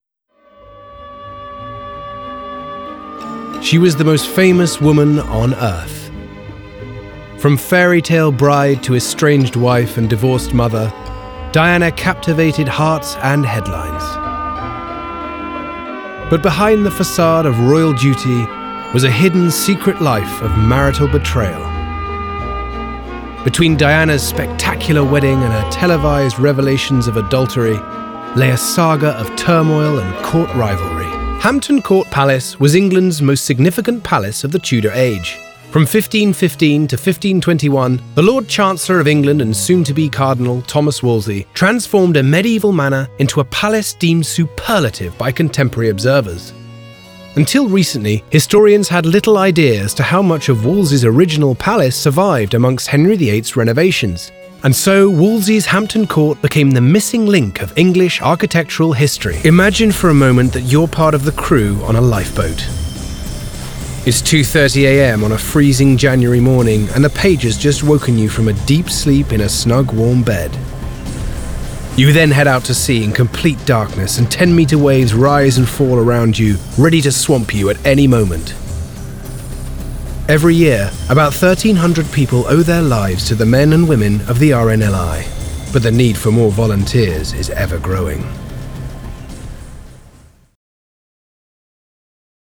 • Male
• Standard English R P